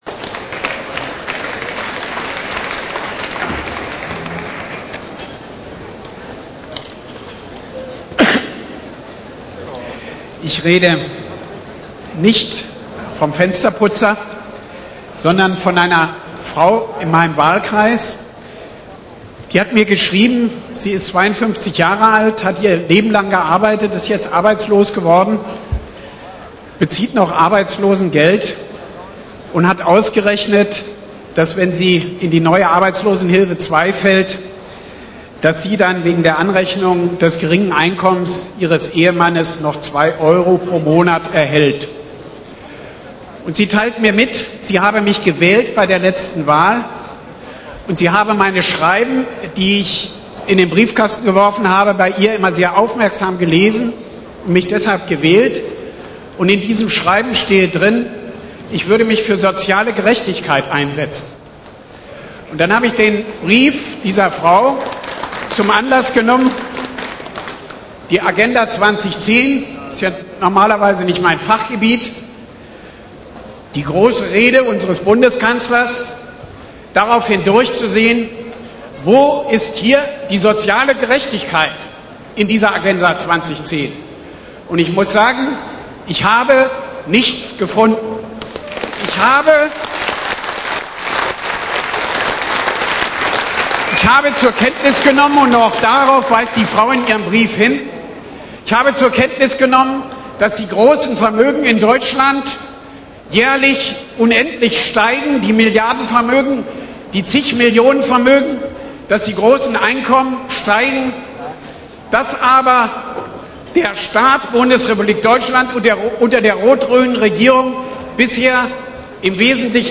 Parteitagsrede zur Vermögenssteuer
29.07.2003: Als Video: Rede von Hans-Christian Ströbele zur Vermögenssteuer auf der Bundesdelegiertenkonferenz von Bündnis 90/Die Grünen am 15.6.2003 in Cottbus